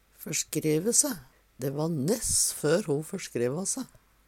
førskreve se - Numedalsmål (en-US)
DIALEKTORD PÅ NORMERT NORSK førskreve se for langt ut med føtene so det gjer vondt Eksempel på bruk Dæ va ness før ho førskreva se.